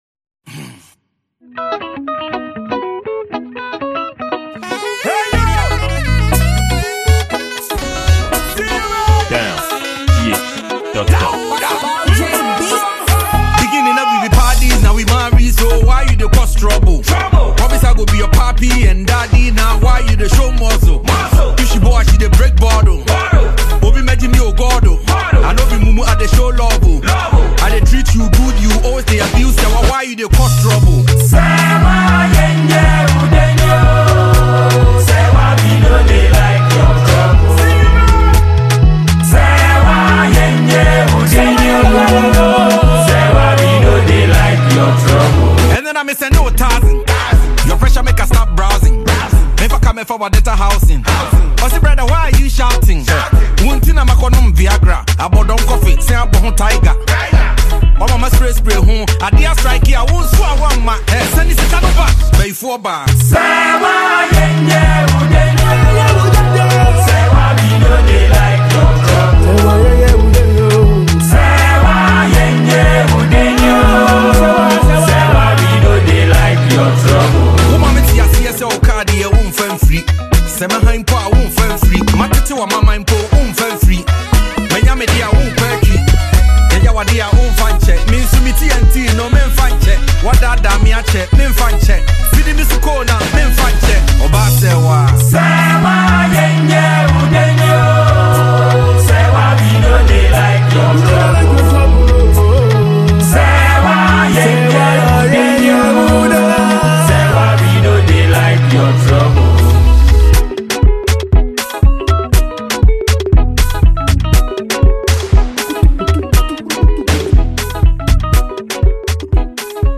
Ghana Music
Hiplife Ghanaian rapper